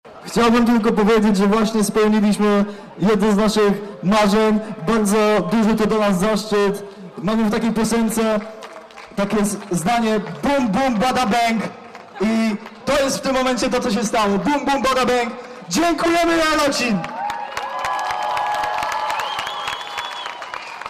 Z wygranej cieszyli się przy głośnych owacjach publiczności: